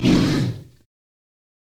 PixelPerfectionCE/assets/minecraft/sounds/mob/polarbear/warning3.ogg at mc116